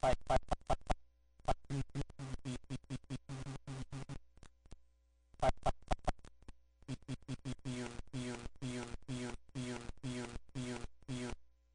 ominous.ogg